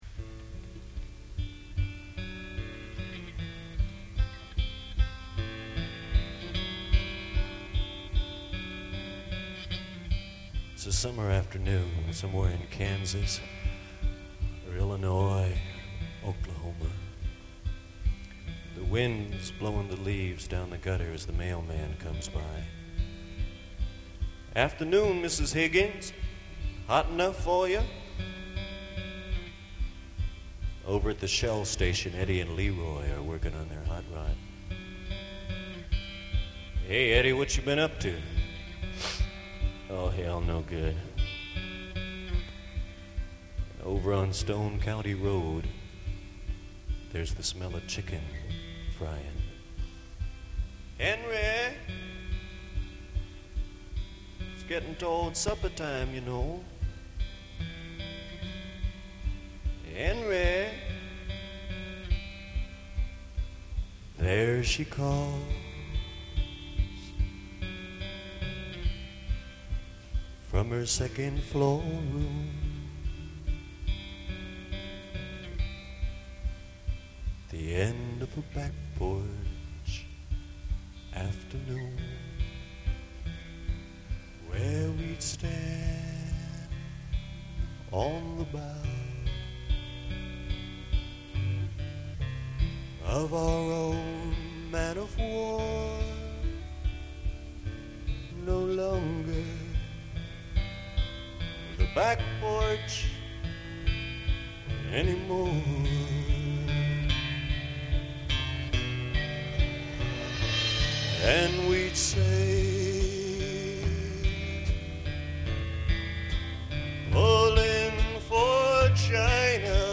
Selected Gig